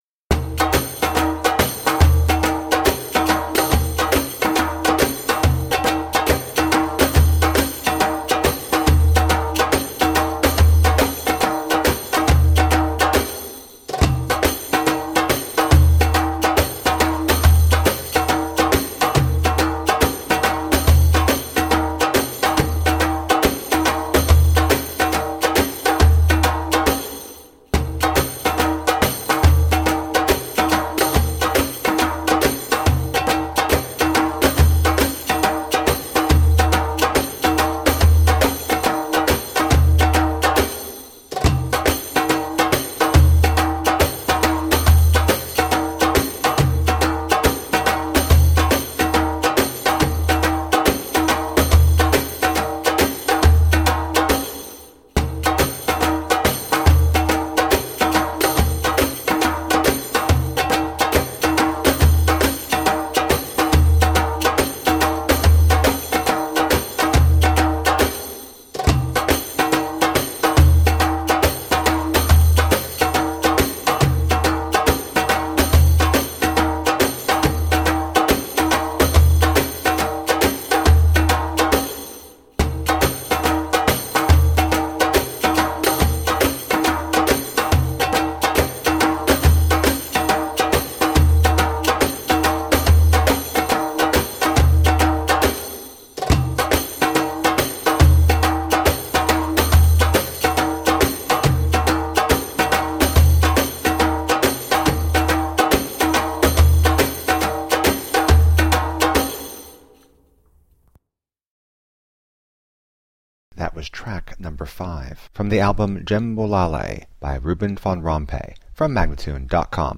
Passionate eastern percussion.
Tagged as: World, Loops, Arabic influenced